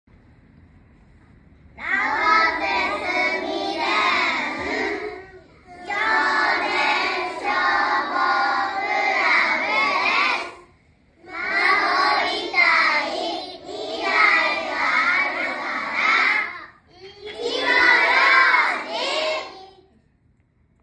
「火災予防運動」や「歳末警戒」の一環で、管内の子ども達（幼年消防クラブ員）が録音した音声を消防車から流し、パトロールをします。
子ども達は、かわいい元気いっぱいの声で「守りたい 未来があるから 火の用心」と音声を録音して協力して頂きました。
広報音声（順不同）